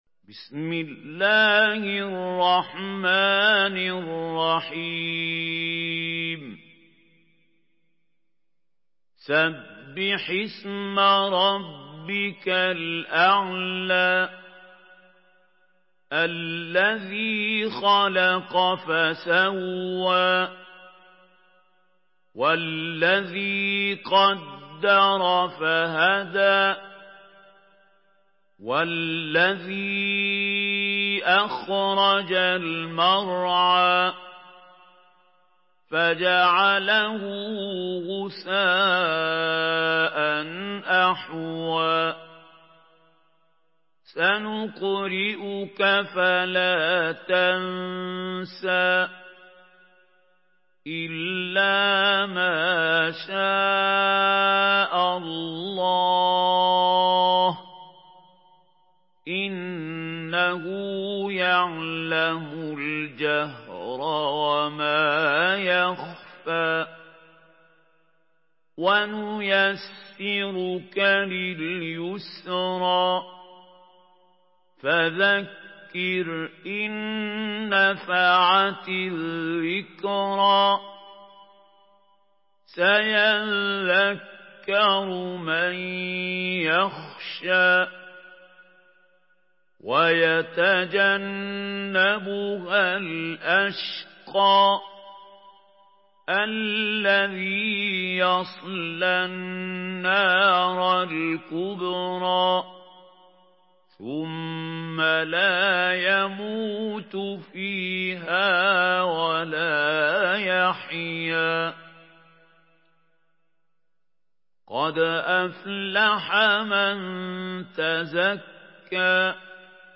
Surah আল-আ‘লা MP3 in the Voice of Mahmoud Khalil Al-Hussary in Hafs Narration
Murattal Hafs An Asim